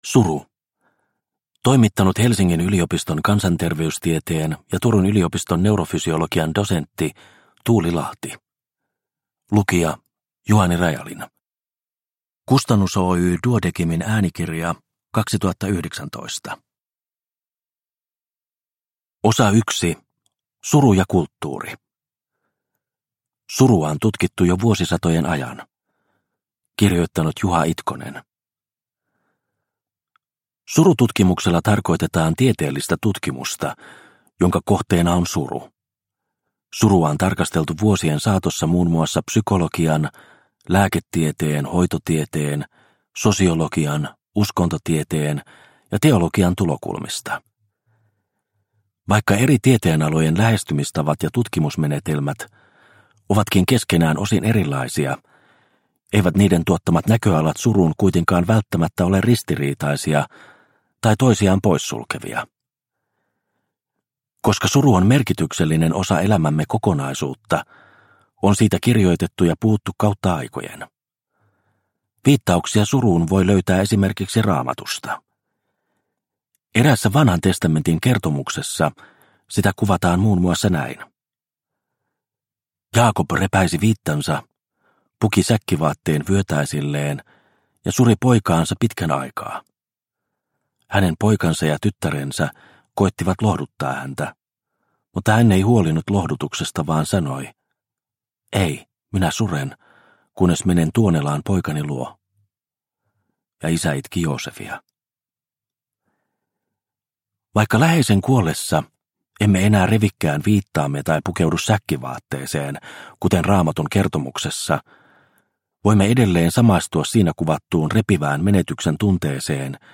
Suru – Ljudbok – Laddas ner